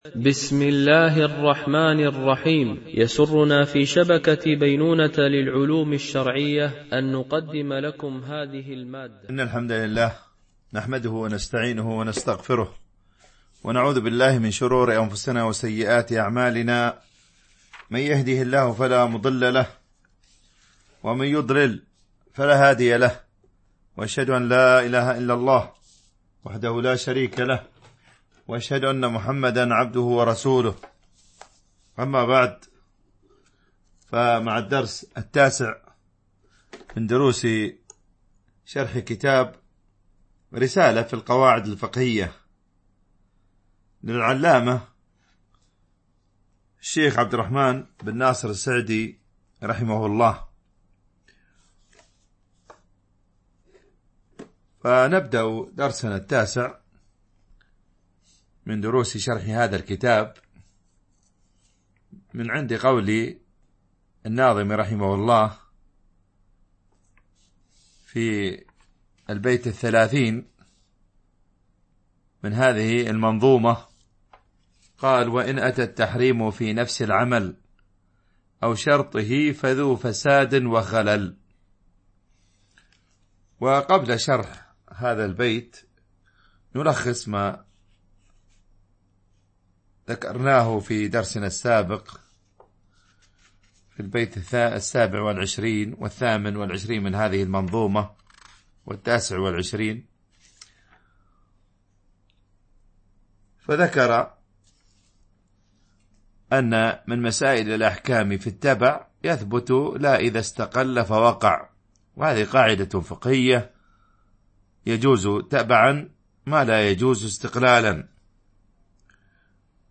شرح القواعد الفقهية ـ الدرس 9 ( النهي يقتضي الفساد )